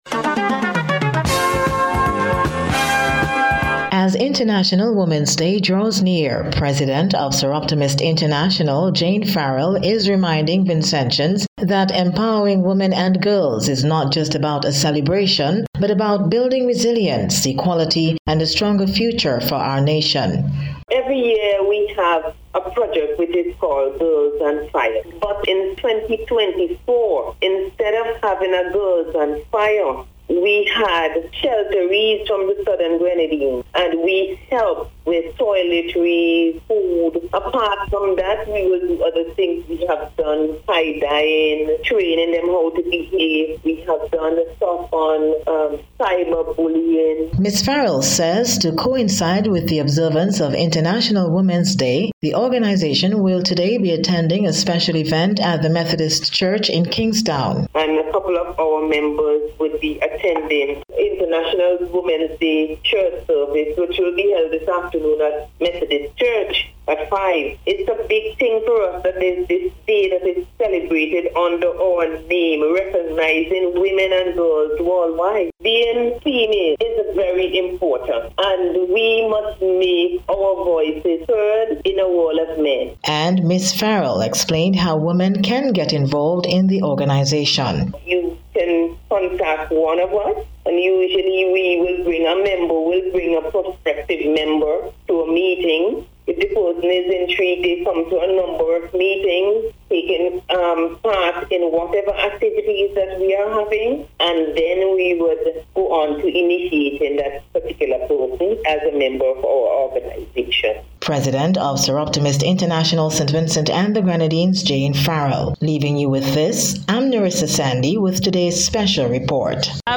INTERNATIONAL-WOMENS-DAY-SPECIAL-REPORT.mp3